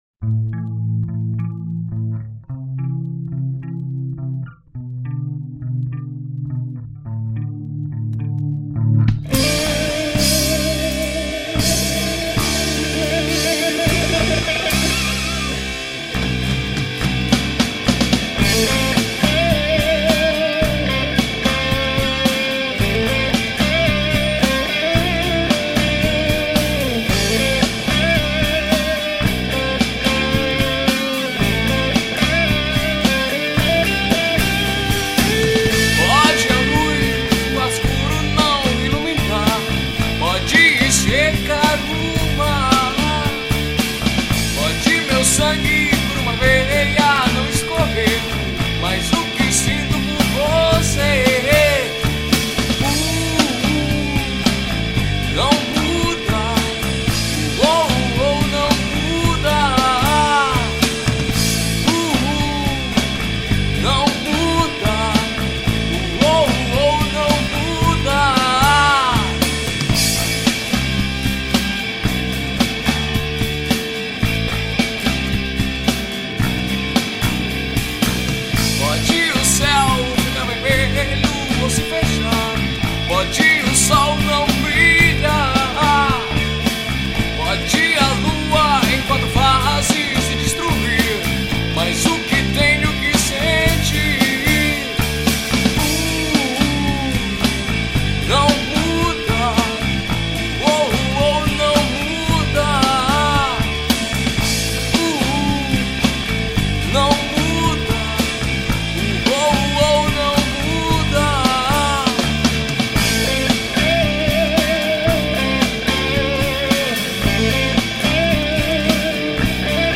2508   03:55:00   Faixa: 6    Rock Nacional